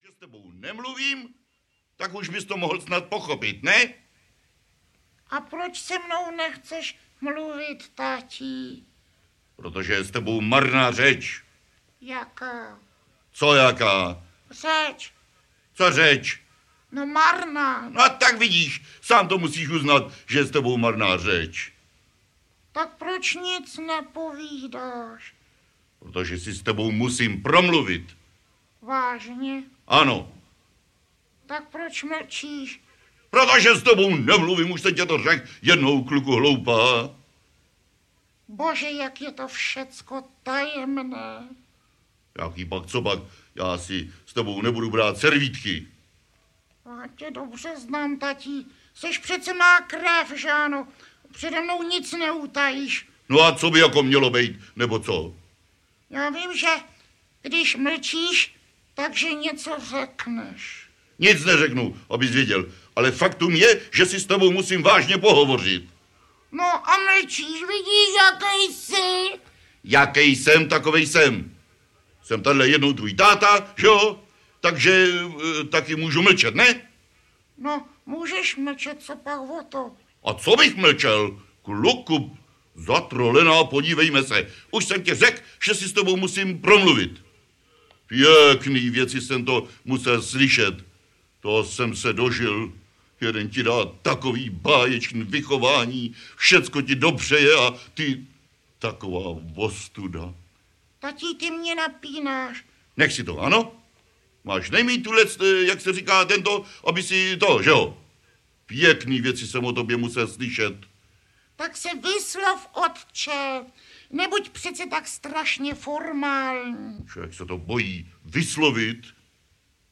Panu Spejblovi je letos 100 let - a tak to řádně slaví Kompilace nejzajímavějších archivních nahrávek, doplněný úplnými novinkami a jedním vzácným objevem.
Ukázka z knihy